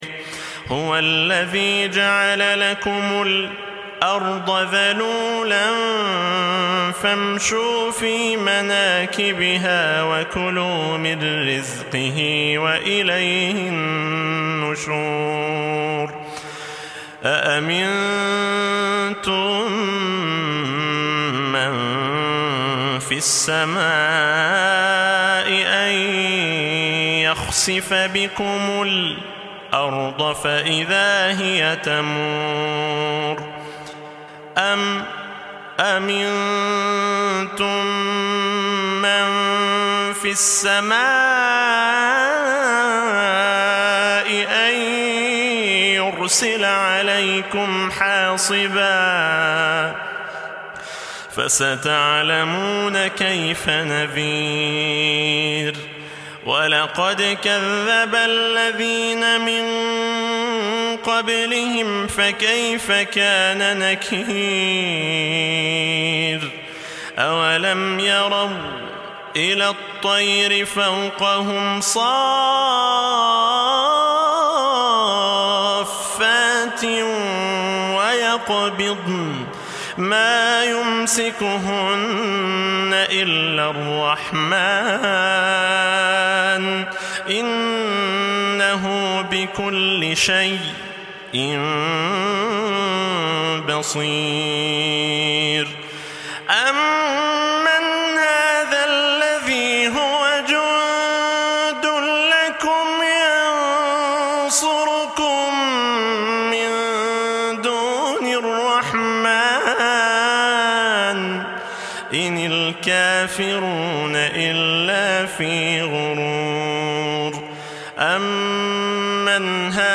برواية خلاد من صلاة التروايح Sound Effects Free Download